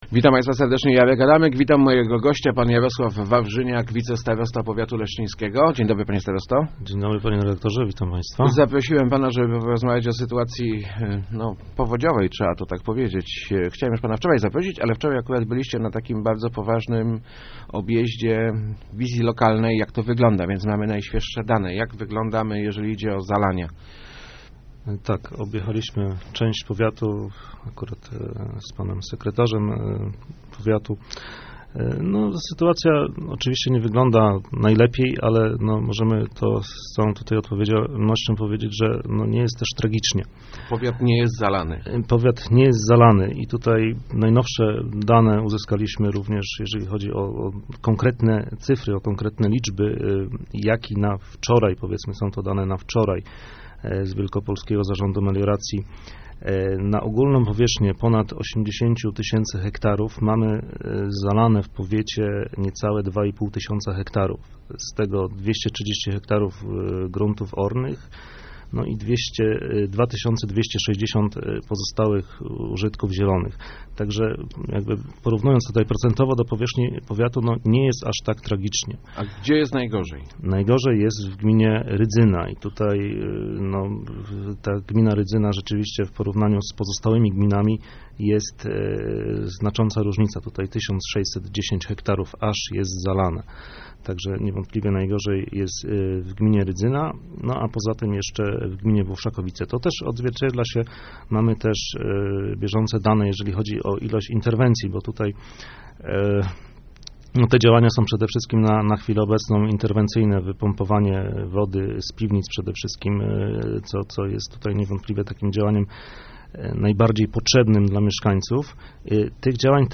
Na 80 tysięcy hektarów powiatu pod wodą znajduje się niespełna dwa i pół tysiąca - mówił w Rozmowach Elki Jarosław Wawrzyniak, wicestarosta Leszczyński.